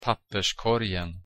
papperskorgen –  (‘паппэ ‘корьэн) урна для мусора